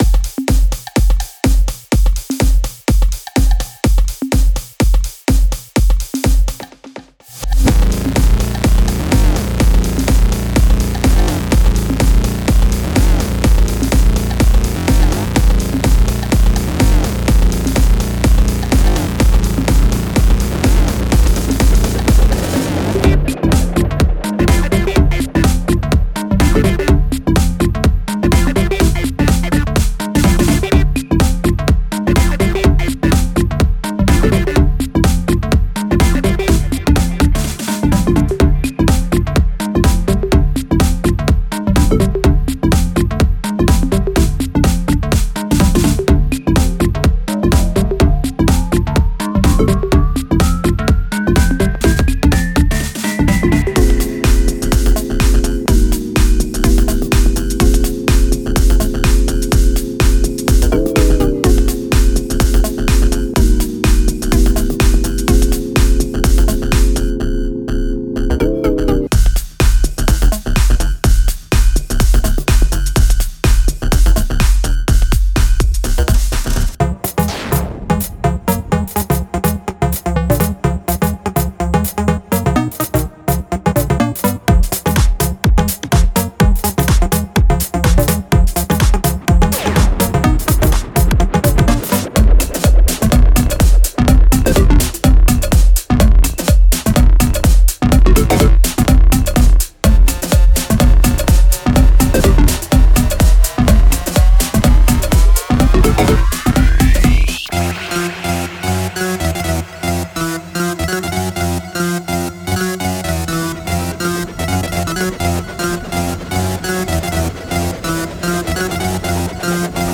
heavy Deep House / Electro House / EDM all-rounder pack
150 One Shots (Kick, Hihat, Snare, Clap, Perc)